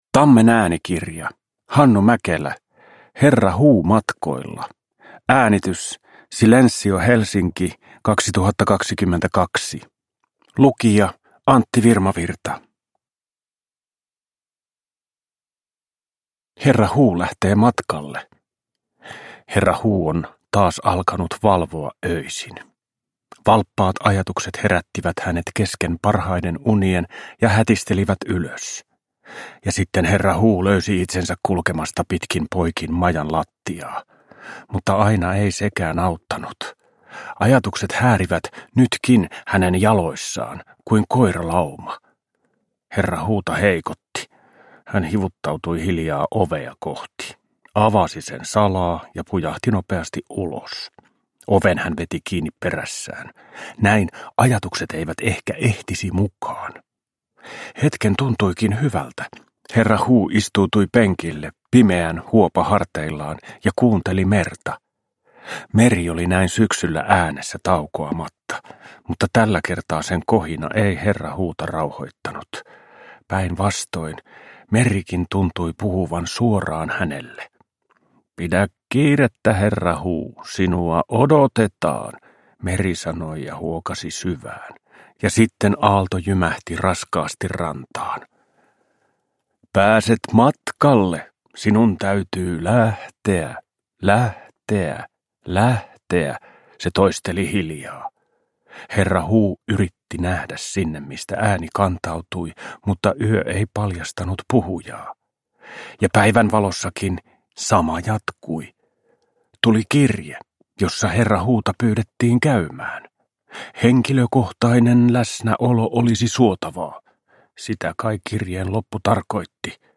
Herra Huu matkoilla – Ljudbok – Laddas ner
Uppläsare: Antti Virmavirta